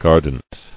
[gar·dant]